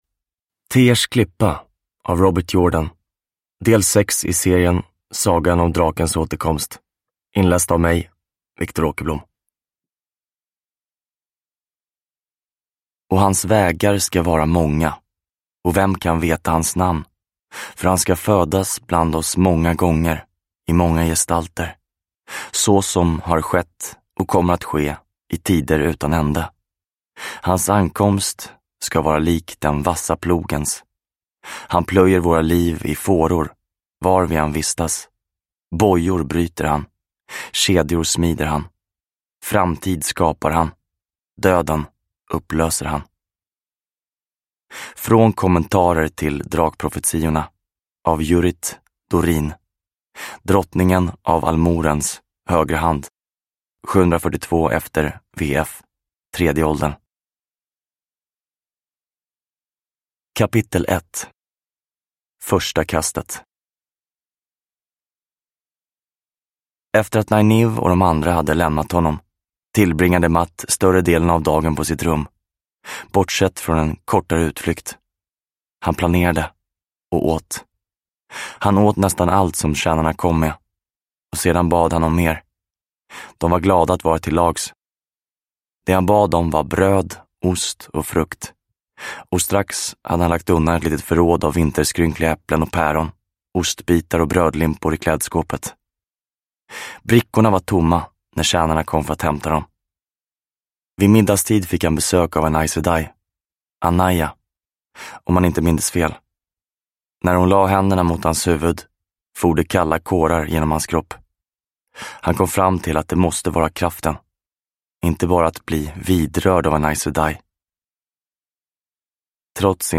Tears klippa – Ljudbok – Laddas ner